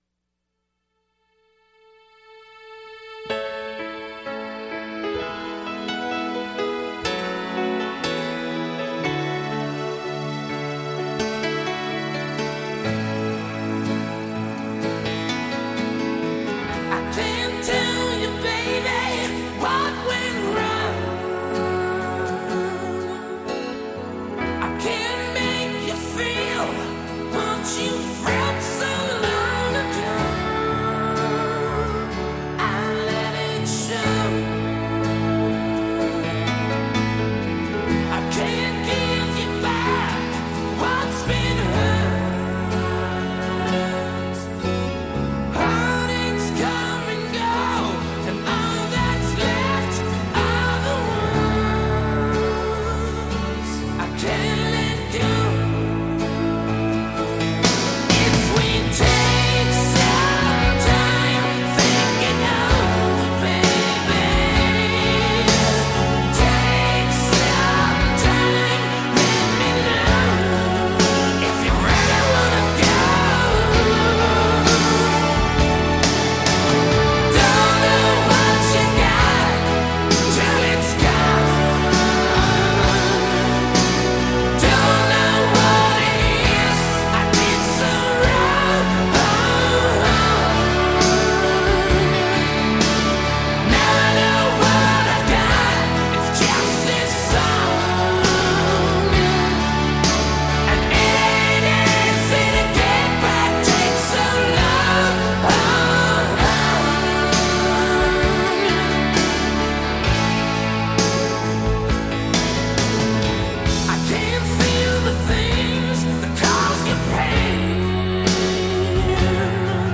1988 Genre: Glam Metal